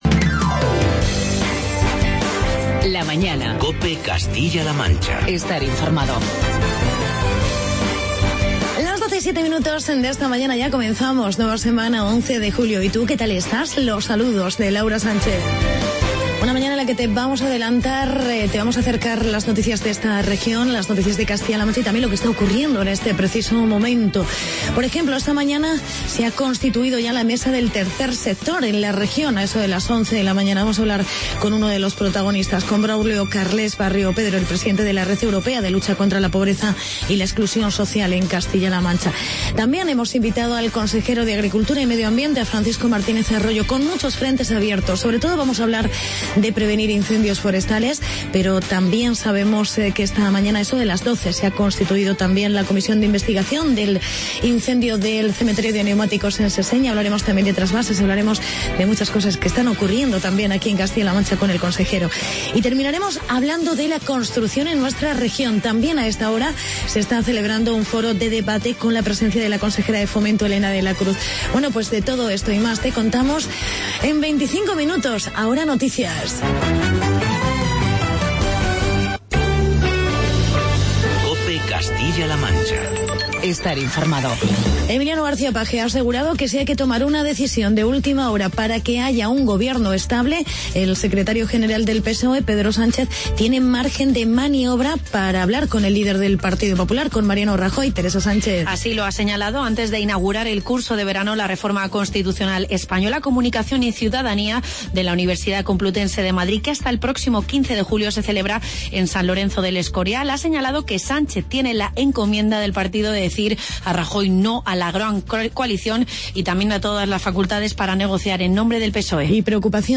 Entrevista con el consejero de Agricultura, Medio Ambiente y Desarrollo Rural, Francisco Martínez Arroyo.